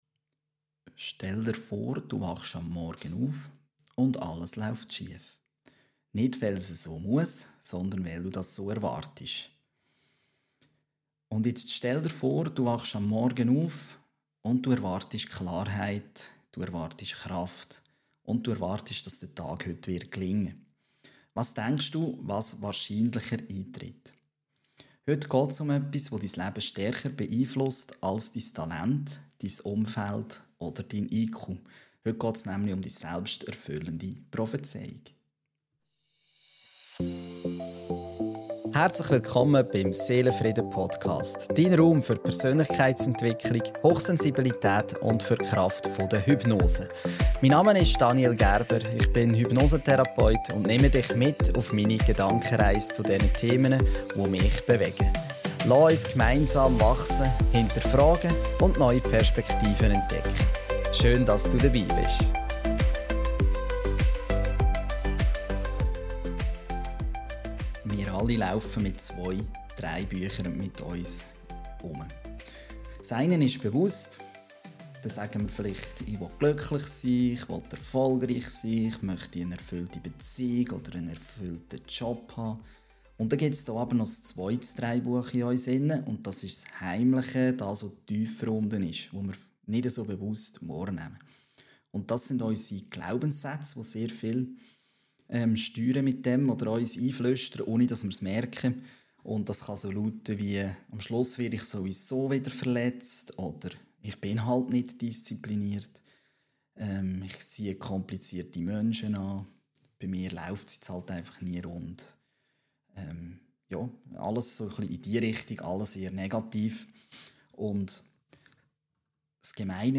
Diese Folge ist anders aufgebaut als üblich: Mit inneren Dialogen, Perspektivwechseln und einer geführten Reflexion, die dich direkt in deine eigenen Muster eintauchen lässt.